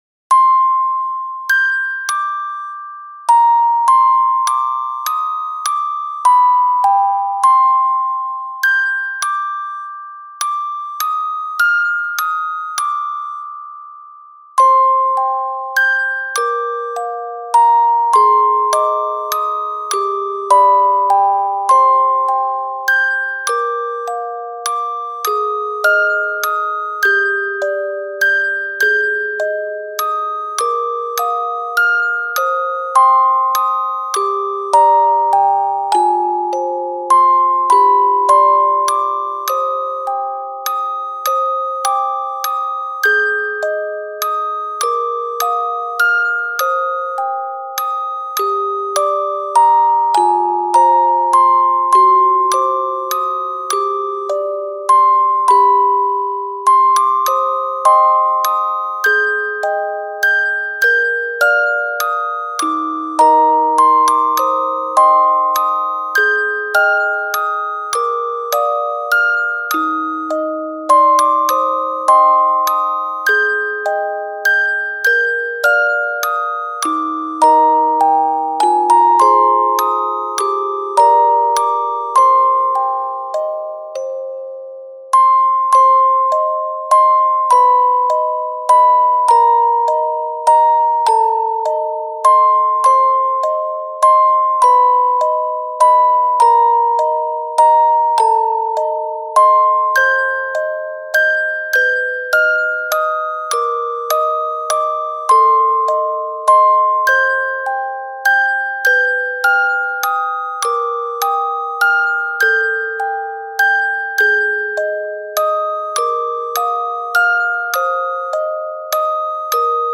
切なく寂しいオルゴールのみのバラードです。
♩=free